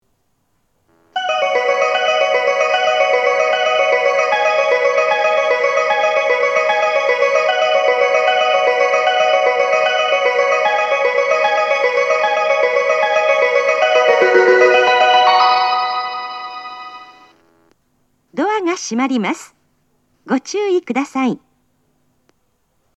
発車メロディー
フルコーラスです。